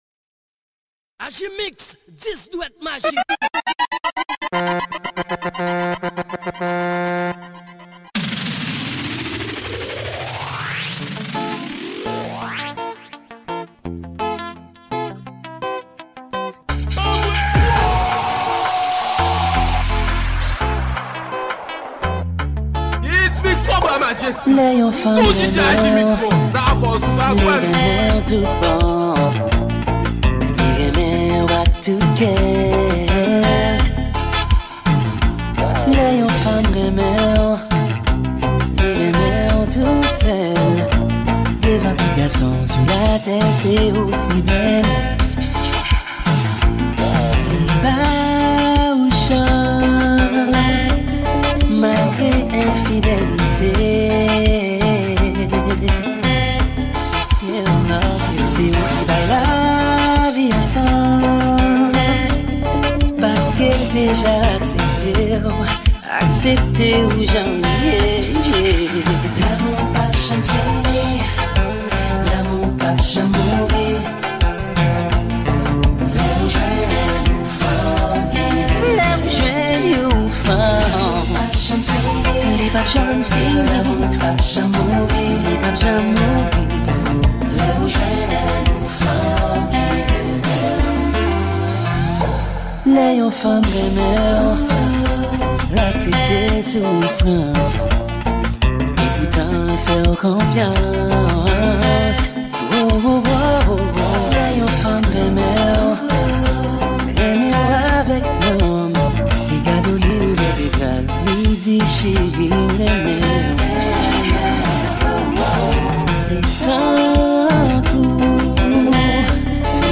Genre: MIXES.